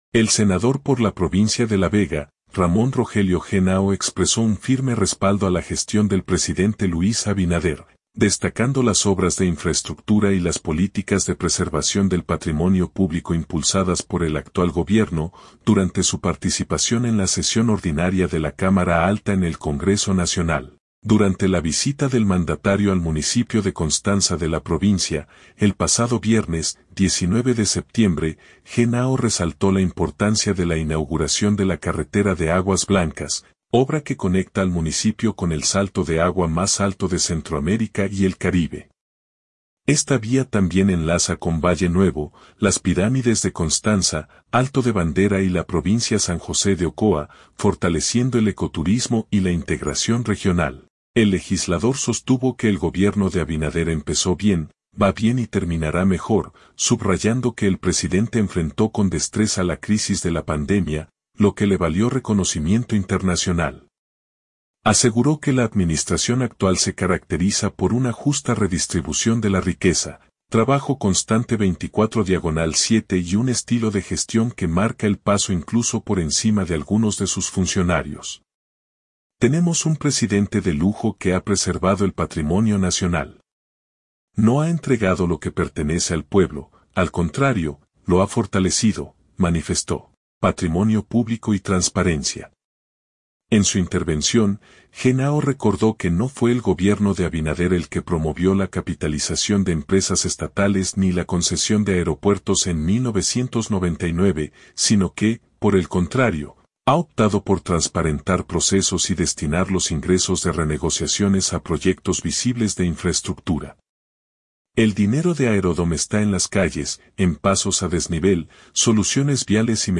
El senador por la provincia de La Vega, Ramón Rogelio Genao expresó un firme respaldo a la gestión del presidente Luis Abinader, destacando las obras de infraestructura y las políticas de preservación del patrimonio público impulsadas por el actual gobierno, durante su participación en la sesión ordinaria de la Cámara Alta en el Congreso Nacional.